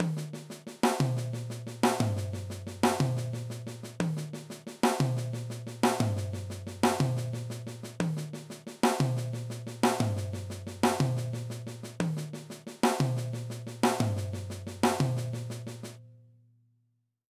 Around the Drums
We’re going to move back to single strokes, but we’re going to play them around the drum set. We’re also going to play them in 16th note triplets so that you get a bouncy feel to the pattern.
Accent the first right hand and the last left hand of every group. You’ll also strike a new drum around the kit with the first right-hand accent.
All the soft notes on the snare drum will be the upstrokes.